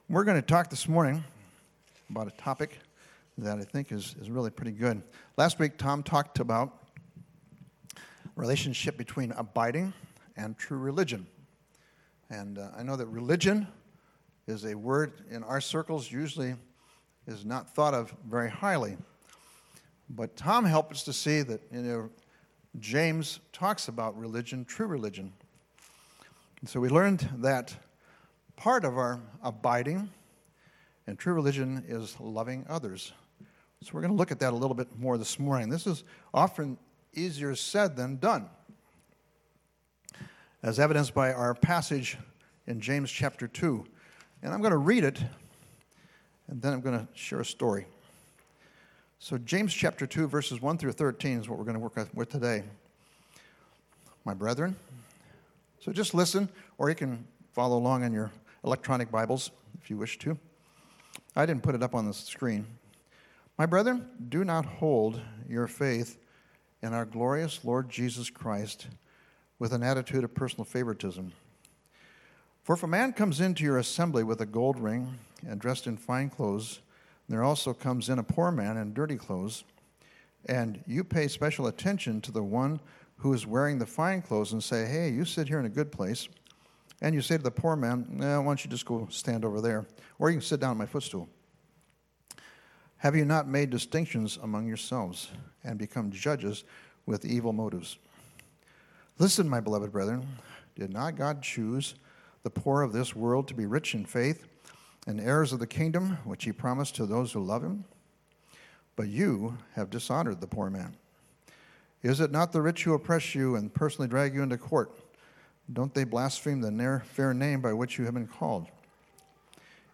Mar 01, 2020 Abiding & Community (03.01.2020) MP3 PDF SUBSCRIBE on iTunes(Podcast) Notes Discussion Sermons in this Series Jesus loves every believer equally, and it breaks His heart that we would show favoritism toward one person or group over another. Truly abiding in Christ will teach us to love equally, and beware partiality.